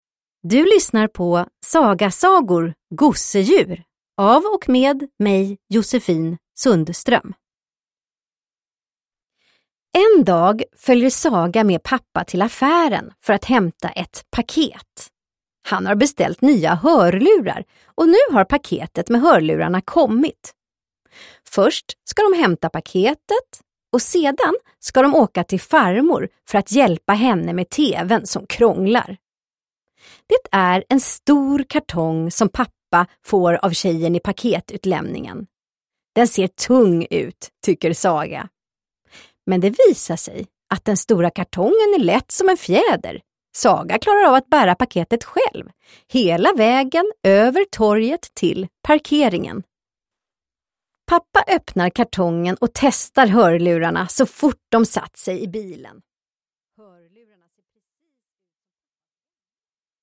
Gosedjur – Ljudbok – Laddas ner